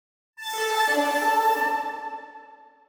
クイズのヒント音
「クイズ系効果音」「ヒント音」